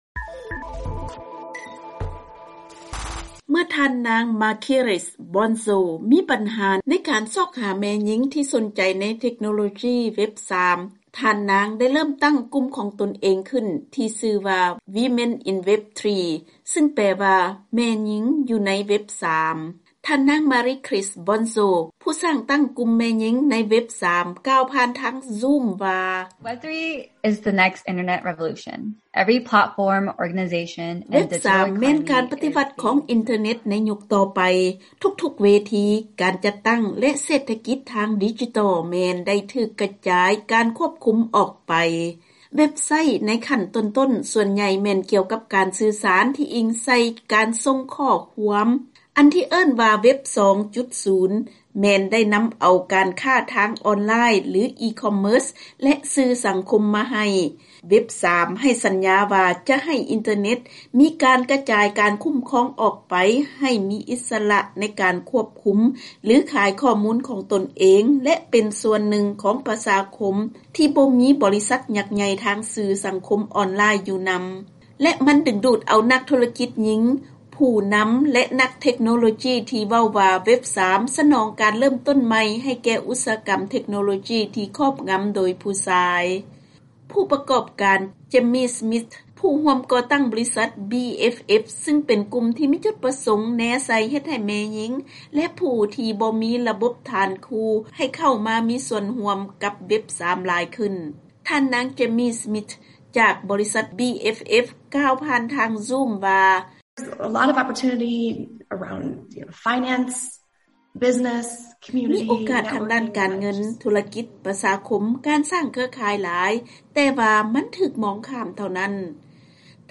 ເຊີນຟັງລາຍງານກ່ຽວກັບແມ່ຍິງກຳລັງສ້າງຕັ້ງເທັກໂນໂລຈີທາງອິນເຕີແນັດໃນຍຸກຕໍ່ໄປ